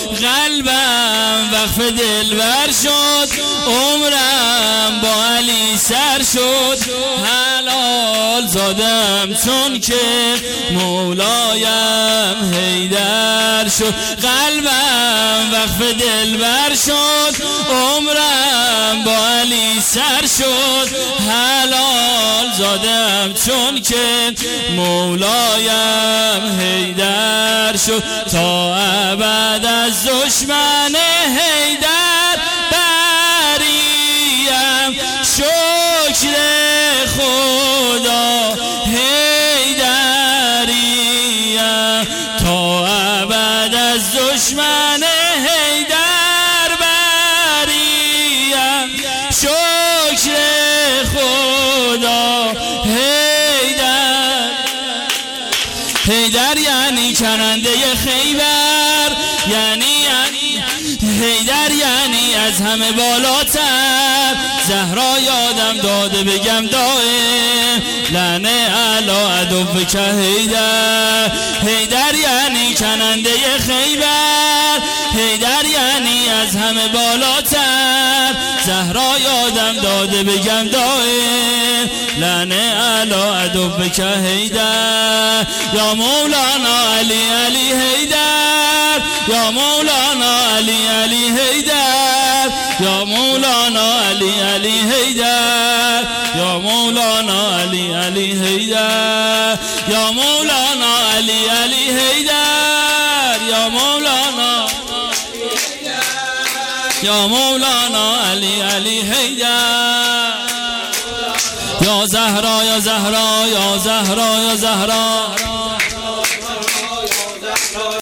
قم جشن میلاد امام جواد(ع) و حضرت علی اصغر (ع)99 اشتراک برای ارسال نظر وارد شوید و یا ثبت نام کنید .